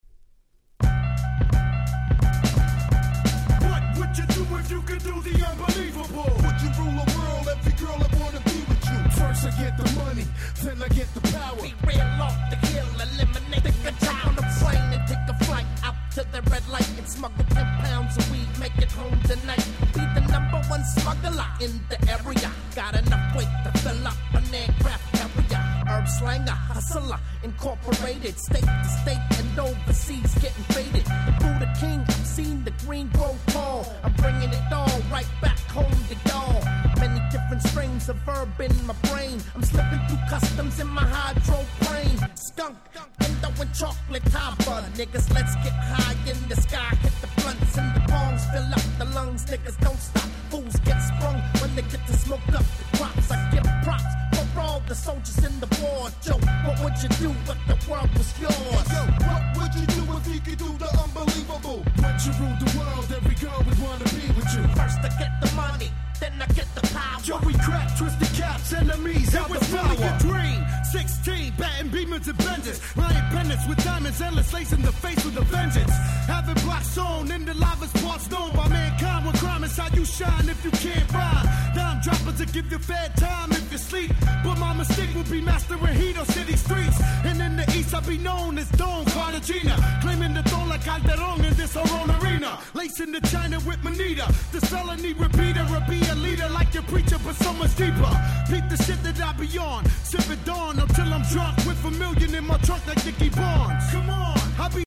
97' Big Hit Hip Hop !!!!